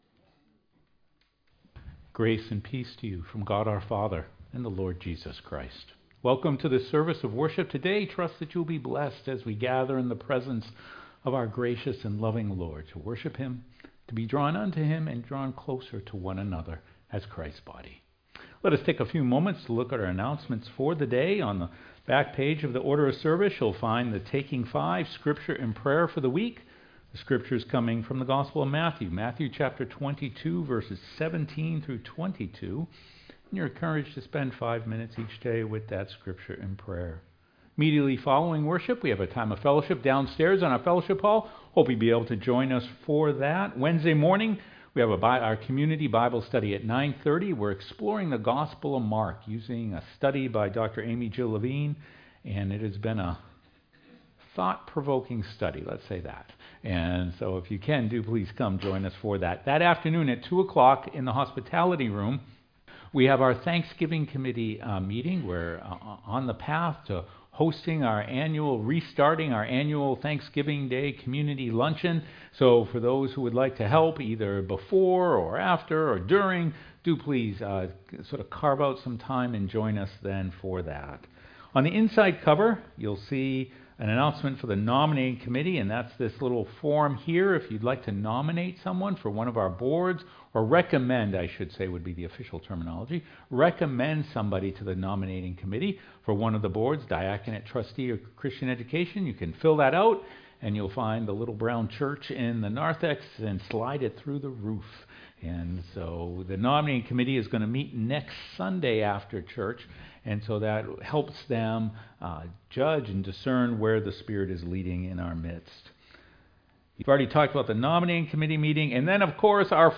sermon-1.mp3